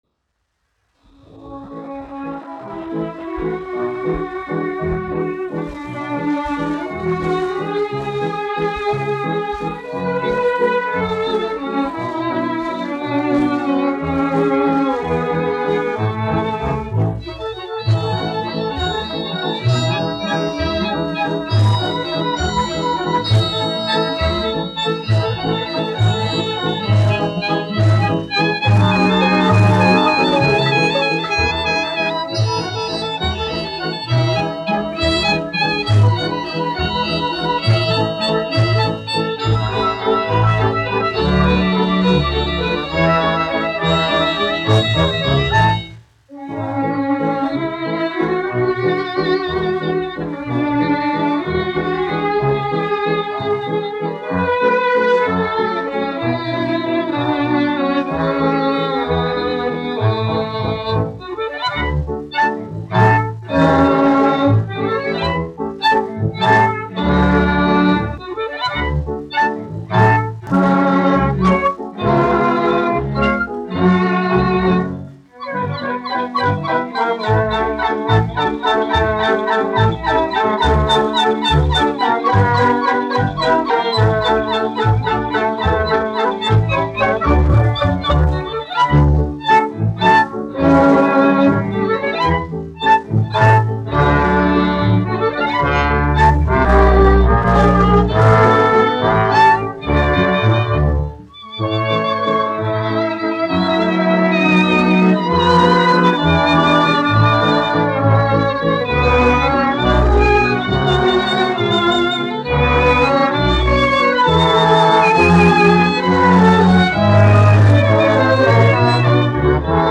1 skpl. : analogs, 78 apgr/min, mono ; 25 cm
Valši
Orķestra mūzika
Skaņuplate
Latvijas vēsturiskie šellaka skaņuplašu ieraksti (Kolekcija)